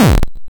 explosion.wav